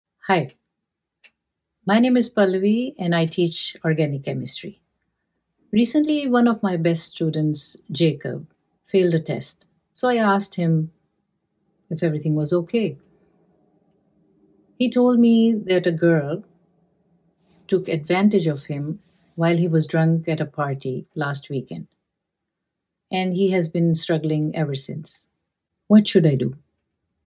Corporate trainer video
The space is fully soundproofed to deliver clean, noise-free recordings.